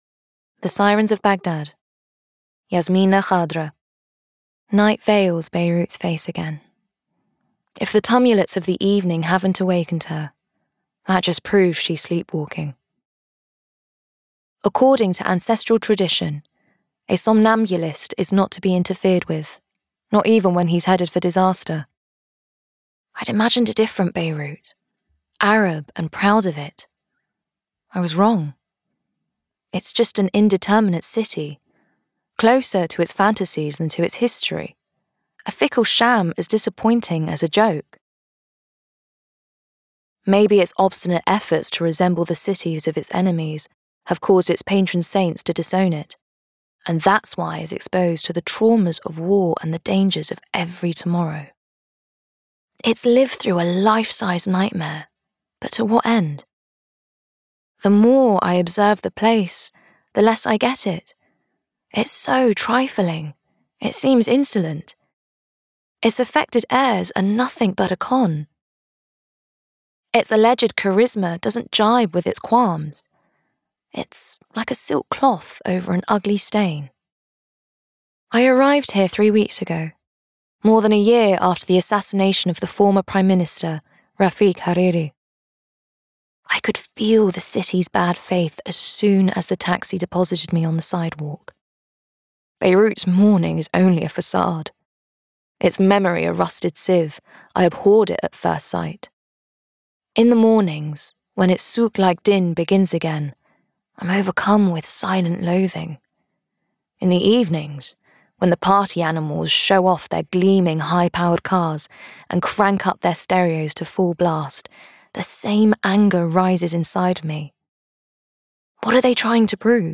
Audiobook Extract
Playing age: Teens - 20s, 20 - 30s, 30 - 40sNative Accent: Arabic, London, RPOther Accents: American, Arabic, Estuary, London, Neutral, RP
• Native Accent: London/RP